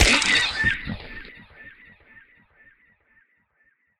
PixelPerfectionCE/assets/minecraft/sounds/mob/horse/skeleton/hit3.ogg at mc116